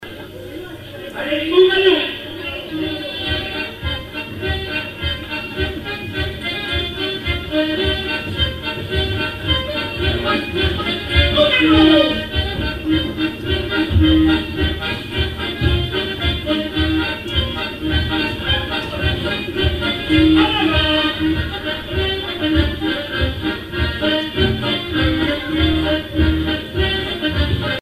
danse : quadrille : galop
airs pour animer un bal
Pièce musicale inédite